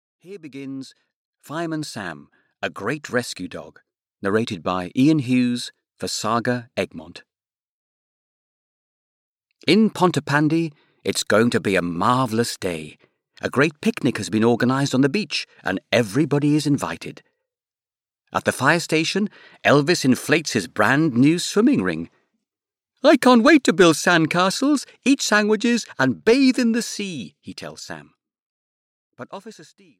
Fireman Sam - A Great Rescue Dog (EN) audiokniha
Ukázka z knihy